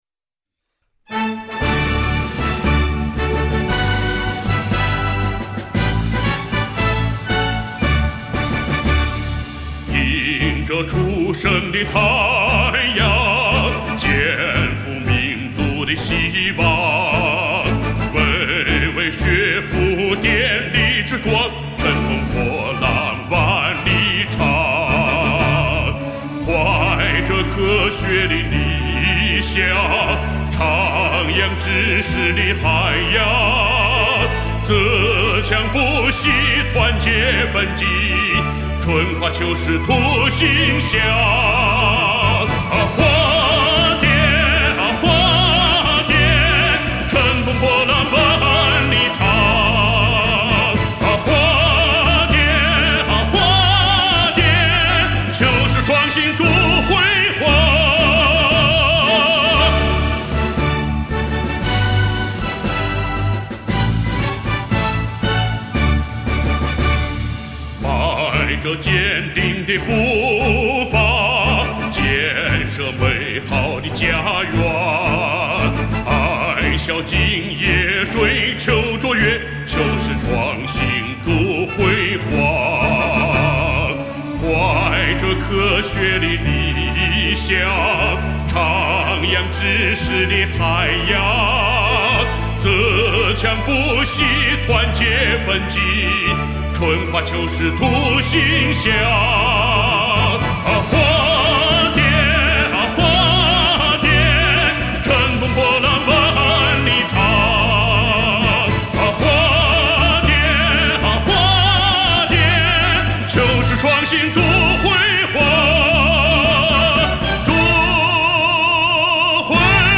著名歌唱家霍勇先生为我校校歌录制独唱版音乐作品 _华北电力大学新闻网
他的音域宽阔，歌声富有穿透力，在中国声乐界评价很高，被誉为“激情澎湃的男中音”。 上一篇 ： 保定校区召开学风建设研讨会 下一篇 ： 我校举行华北电力大学天宇博瑞能源基金签约仪式 相关附件 华北电力大学校歌（演唱：霍勇）